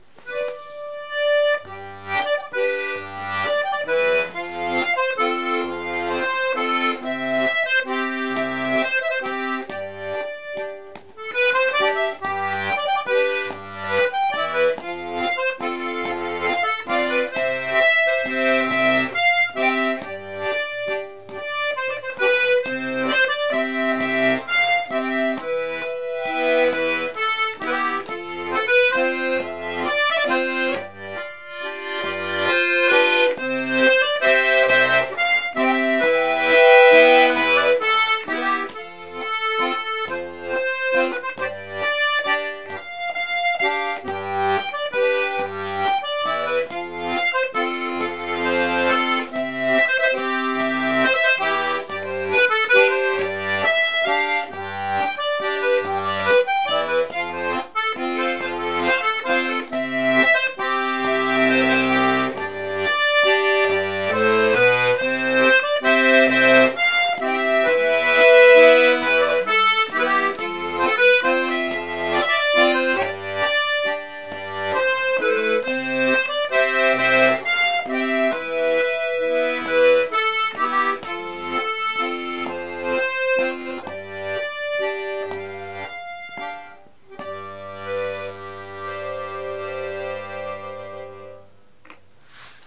l'atelier d'accordéon diatonique
1)    Tes patits patins blancs (valse): la partition